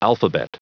Prononciation du mot alphabet en anglais (fichier audio)
Prononciation du mot : alphabet